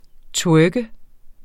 Udtale [ ˈtwœːgə ]